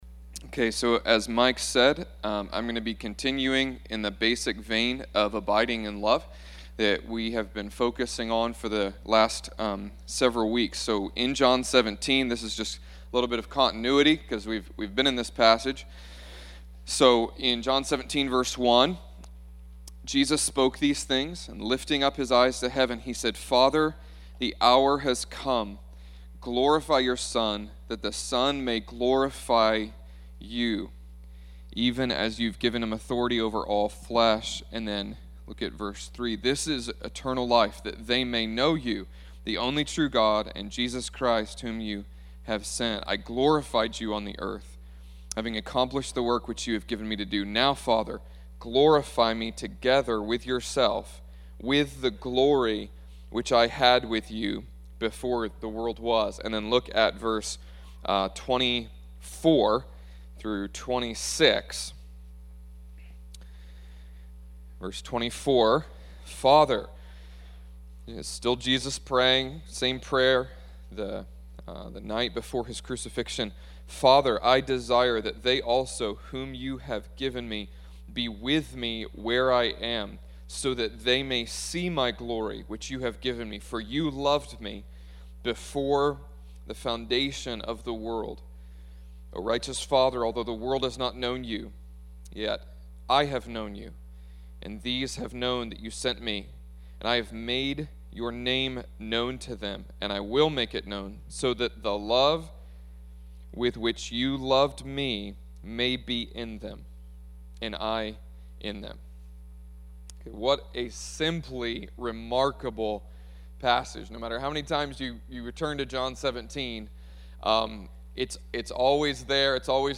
ServiceForerunner Christian Fellowship